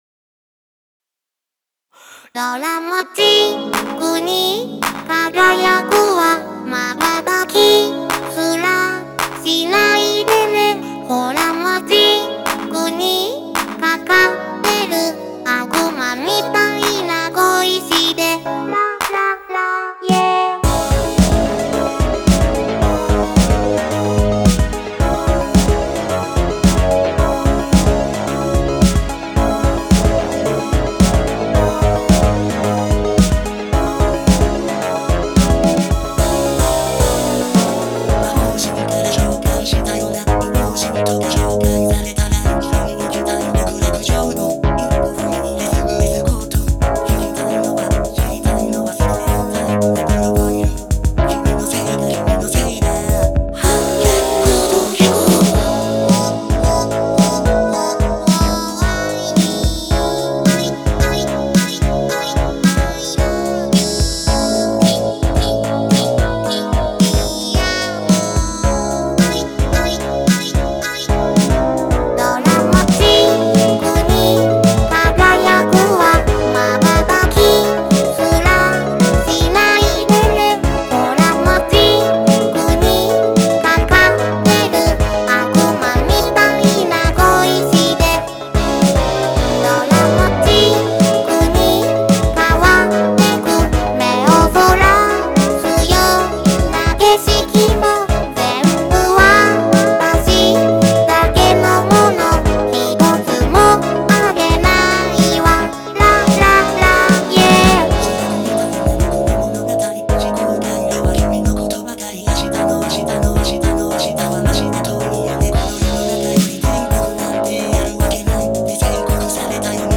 ジャンル: VOCALOID系。